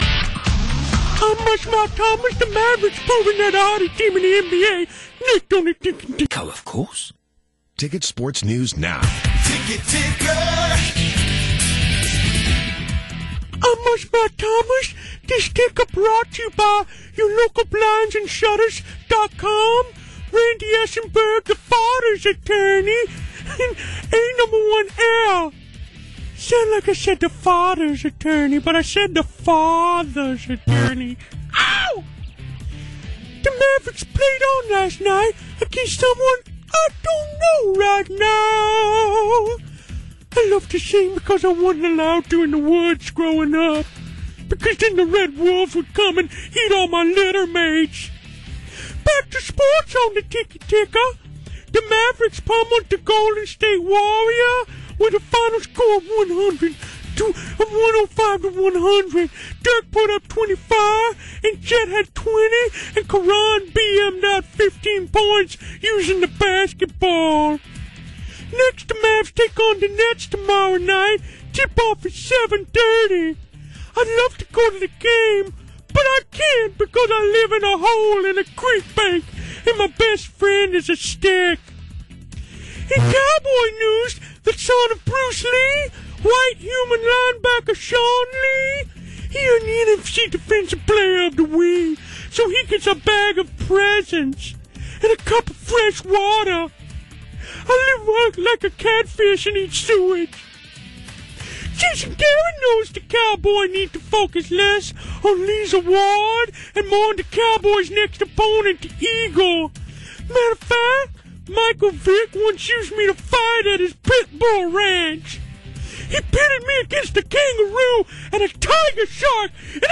hehe…when he says he “fought” mike vick, I swear it sounds like he “f!cked” mike vick…he says it so passionately too, i can’t get that out of my head!